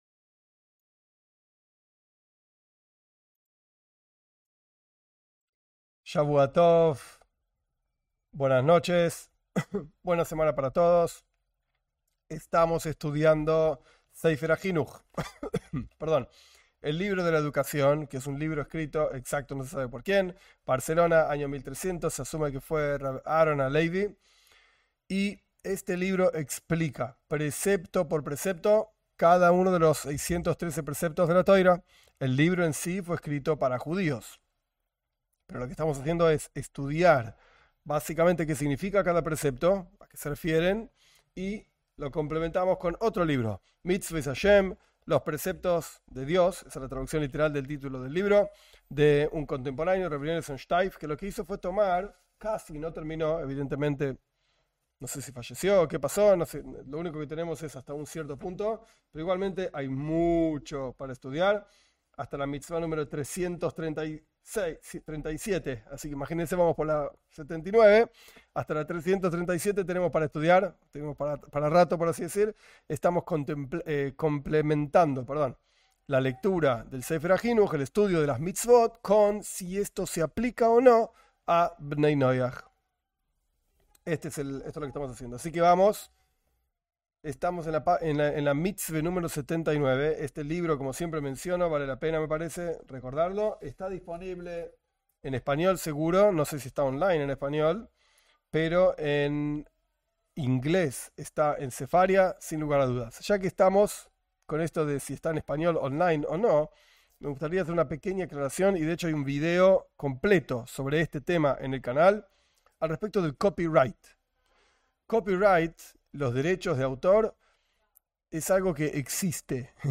En este curso estudiaremos los preceptos del judaísmo en forma breve, basándose en el libro de la educación (Sefer HaJinuj) y aplicándolos a Bnei Noaj de acuerdo a los escritos de Rabí Ionatan Steiff (1877-1958). En esta clase estudiamos los preceptos relacionados a los Diez Mandamientos.